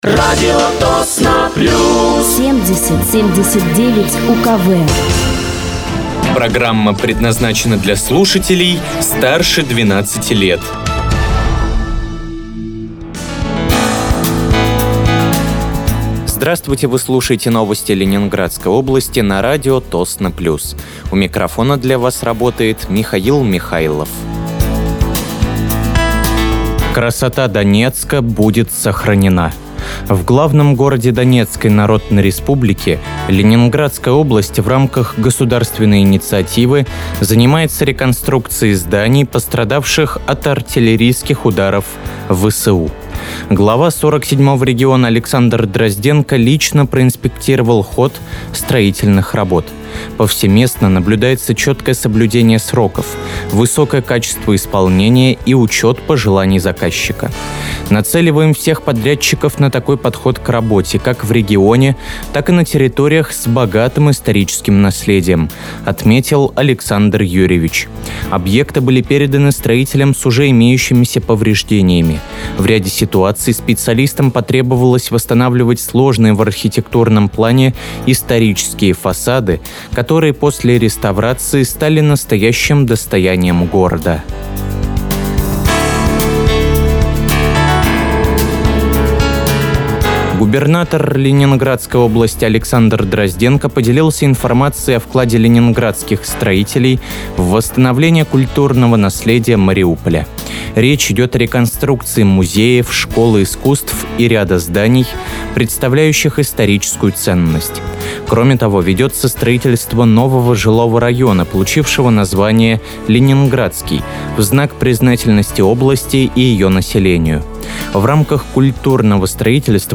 Вы слушаете новости Ленинградской области на радиоканале «Радио Тосно плюс».